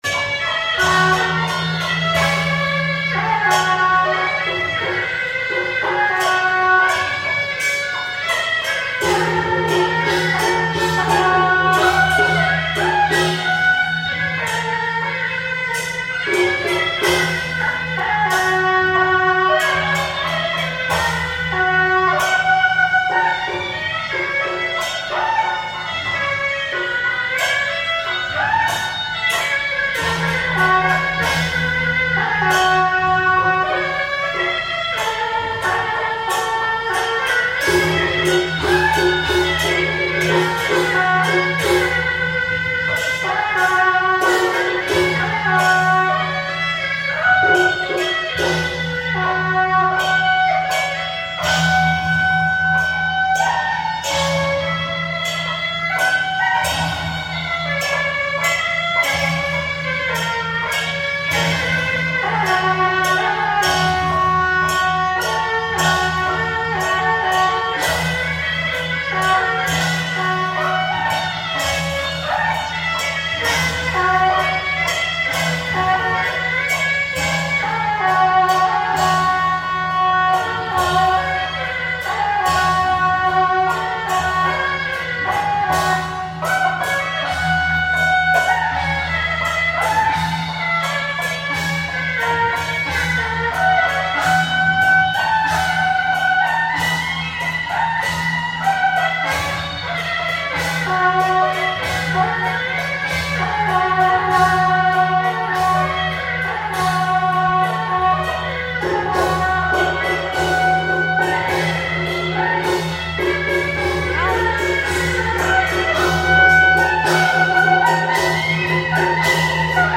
Namensgeber ist der Kronprinz TaiZi der in seinem Tempel, 500m südlich von hier, jeden Sonntag Abordnungen von anderen Tempeln empfängt. Knallerei, riesige Götterfiguren, Medien in Trance und viel Musik mit Schalmei und Schlagwerk: Ihr Browser kann diese Audiodatei nicht wiedergeben. Sie können die Livemusik vom TaiZi-Tempel auch unter diesem Link abrufen.